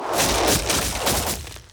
Ice Barrage 2.wav